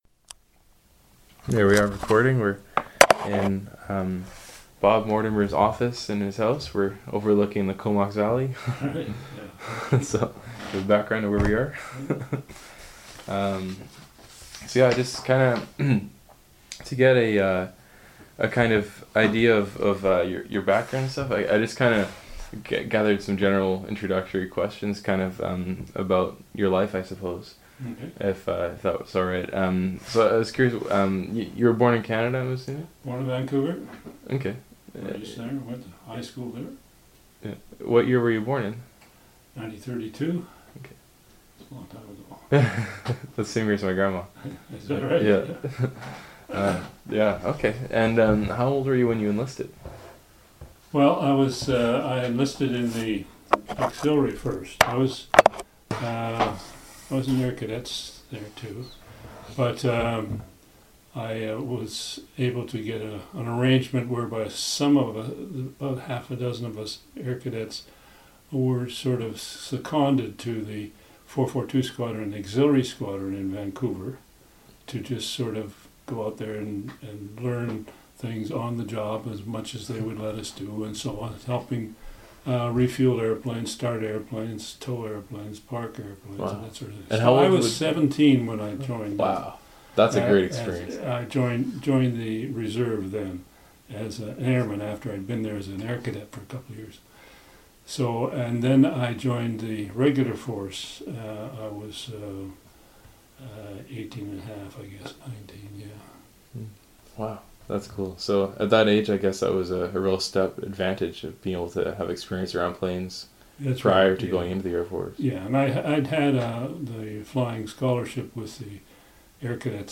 Interview took place on March 9, 2019 in Courtenay, B.C.
Resource type Sound